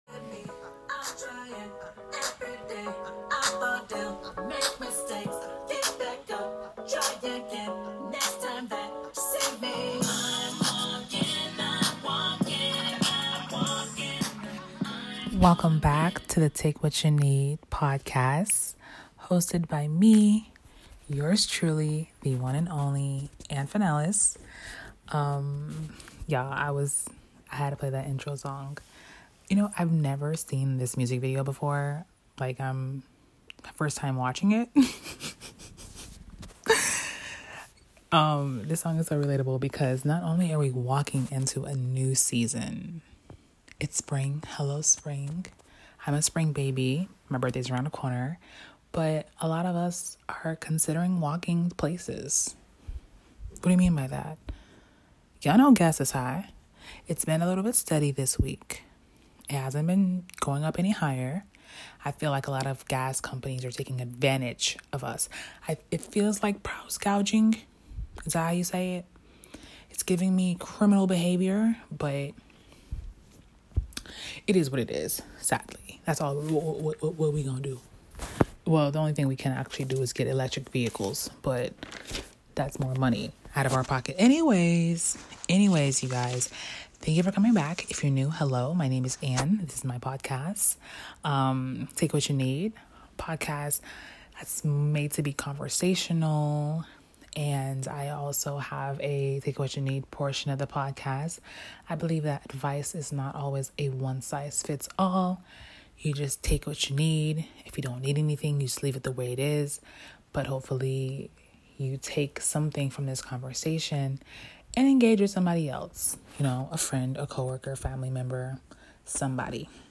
Sorry for sounding so low in this episode. I was super tired, but I promise it won't happen again. In this episode, I talk about understanding the season that you are in.